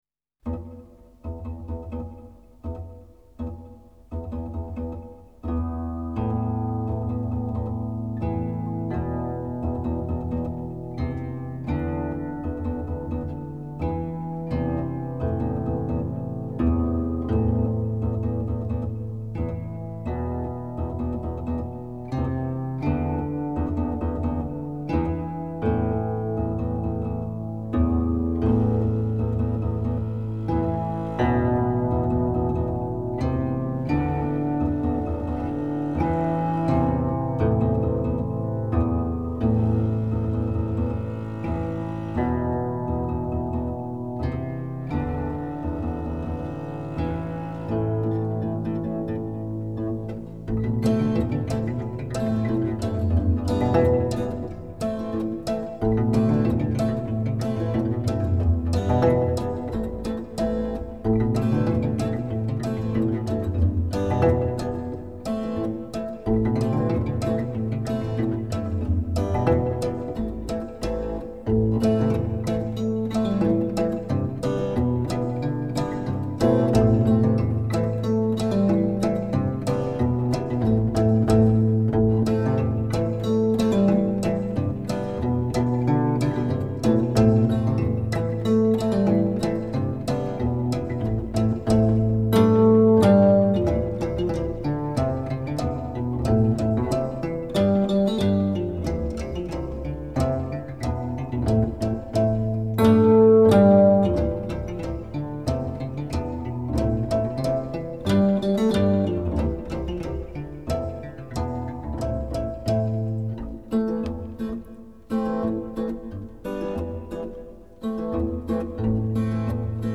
Полька на кантеле